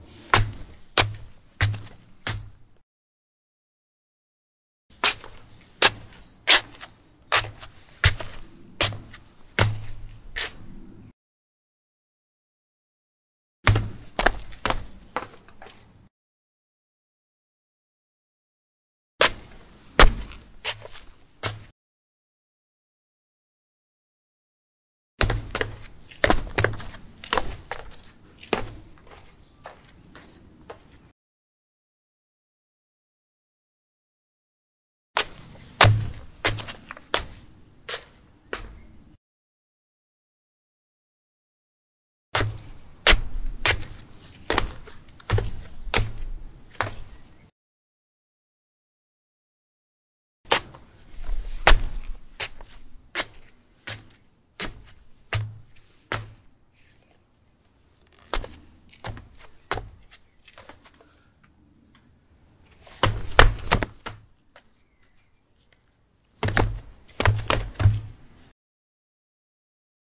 用鞋在瓷砖上行走
描述：当我走过室内油毡地板时，我的脚步声。
Tag: 脚步声 室内 散步 瓷砖